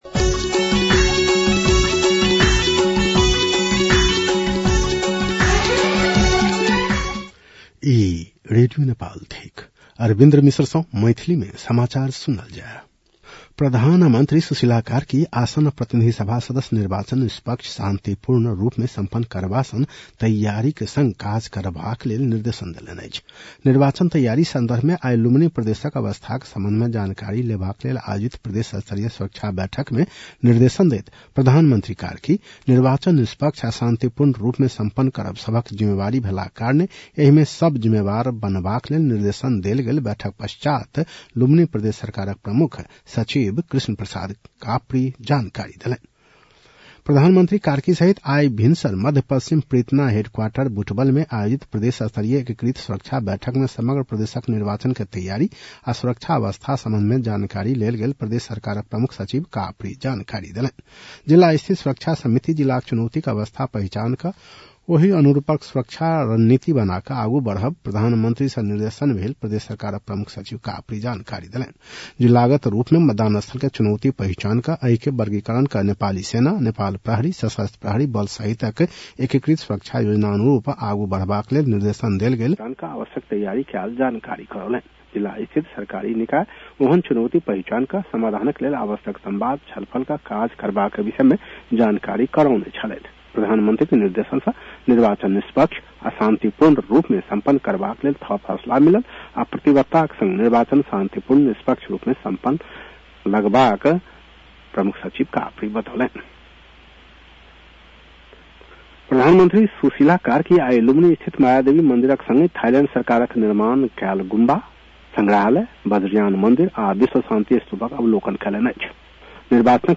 मैथिली भाषामा समाचार : ९ फागुन , २०८२